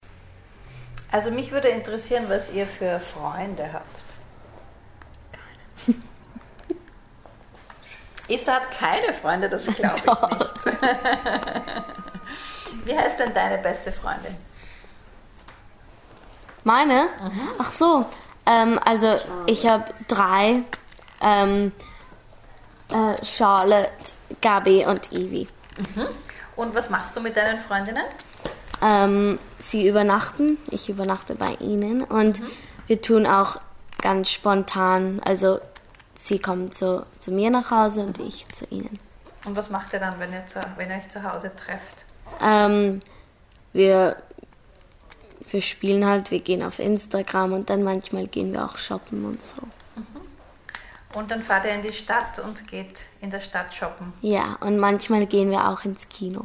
Die Sprecher und Sprecherinnen sprechen dann schneller, sie verwenden mehr Umgangssprache, sie verschlucken einige Silben und beenden ihre Sätze gar nicht richtig.